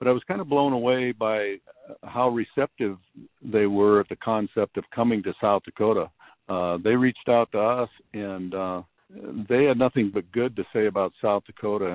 Governor Larry Rhoden: